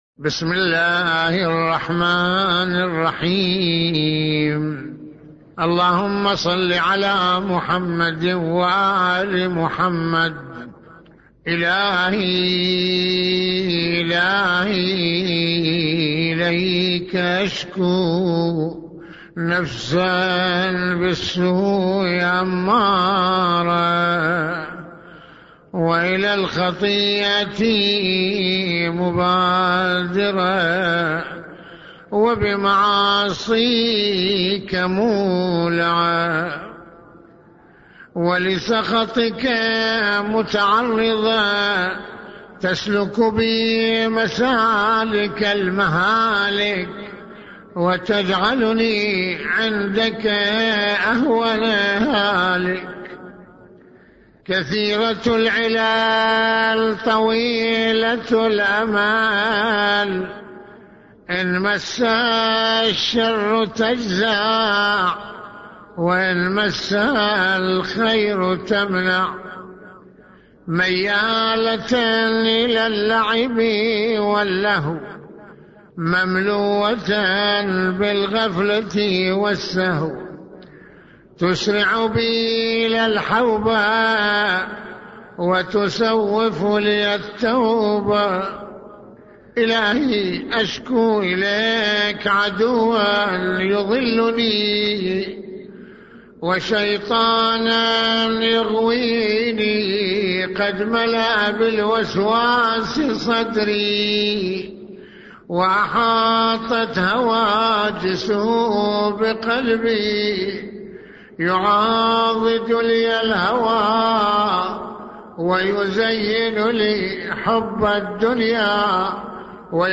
- استمع للدعاء بصوت سماحته